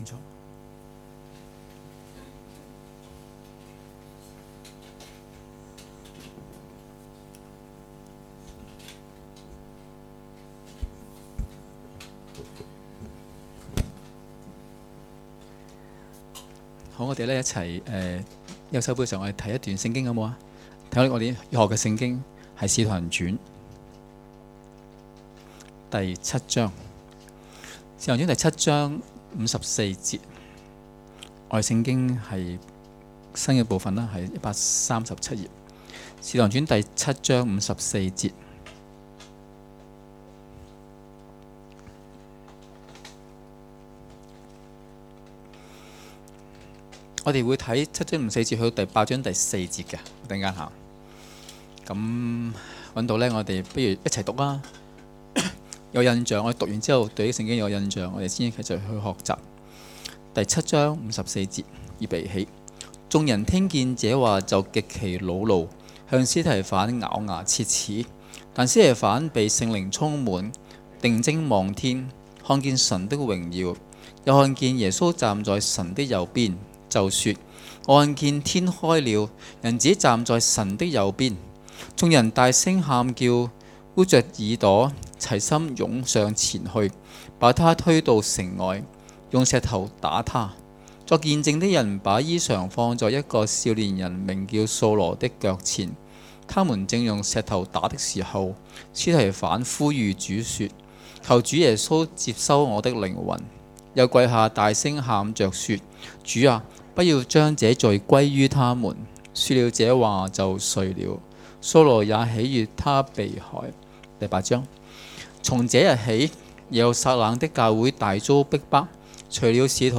崇拜講道